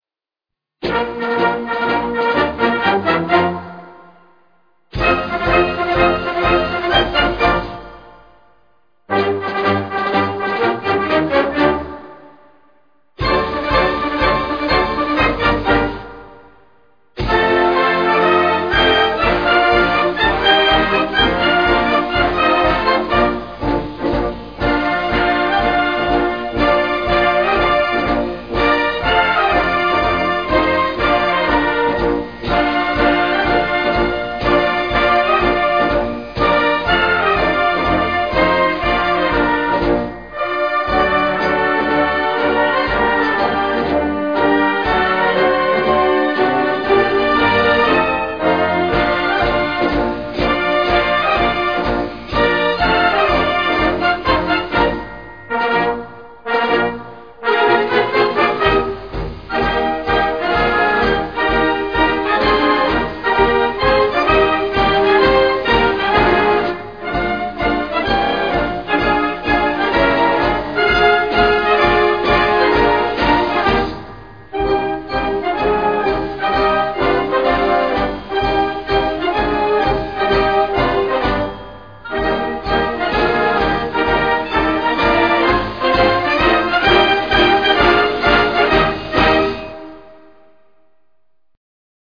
Inno_di_Mameli_instrumental.mp3